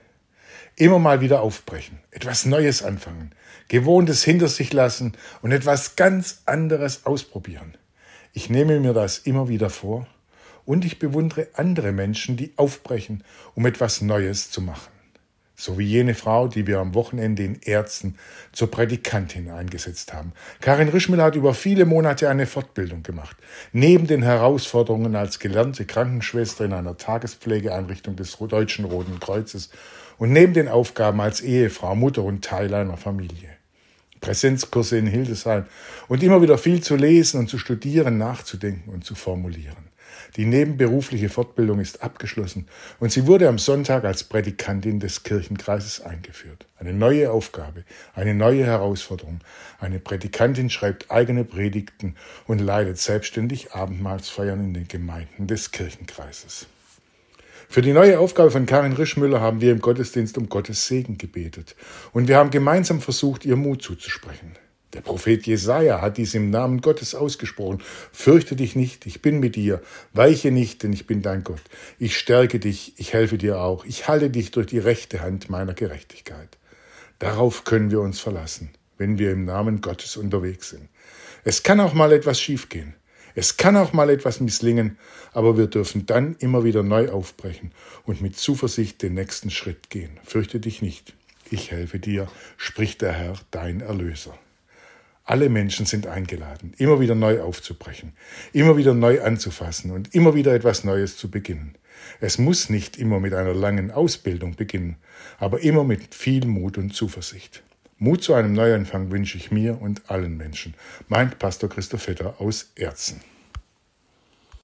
Radioandacht vom 16. September